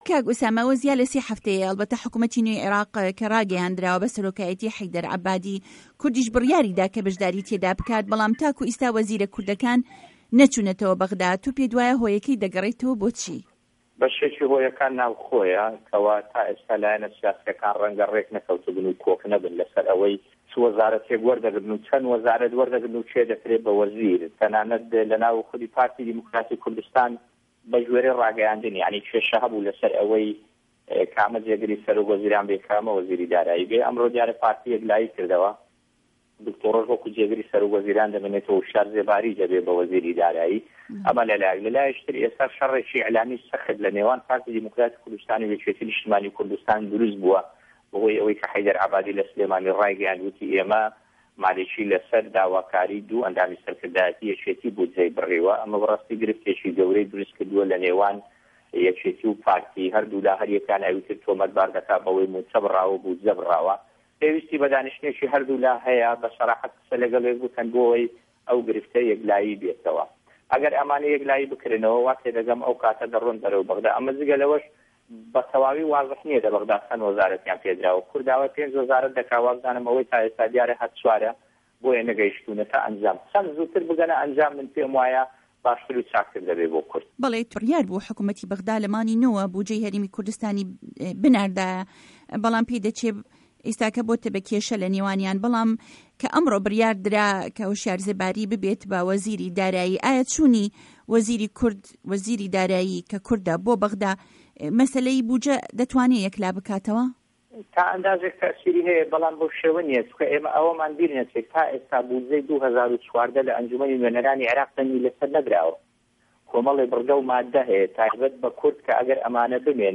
گفتوگۆ